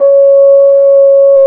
BRS F HRN 0L.wav